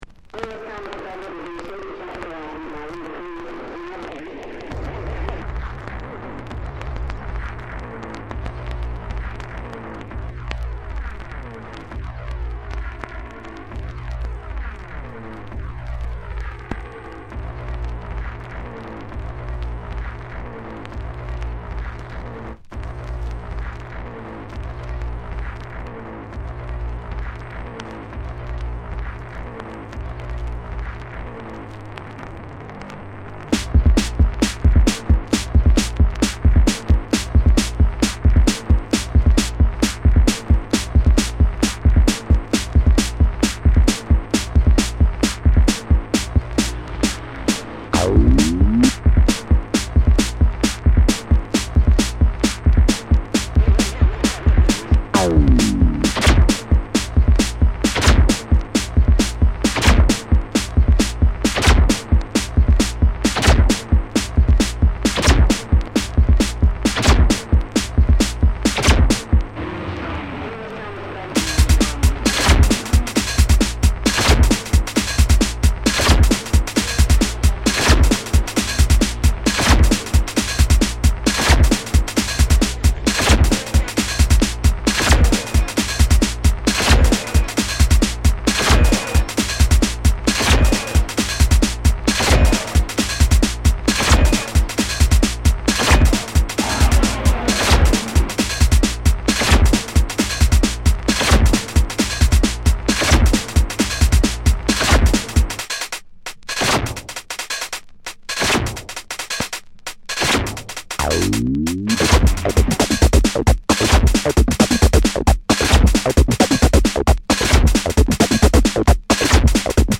この時代ならではの音使いや豪快な展開、それでいて職人気質すぎる几帳面さも垣間見えるのが長く支持される所以なんでしょうね。